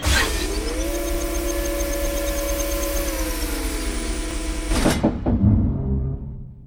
dock2.wav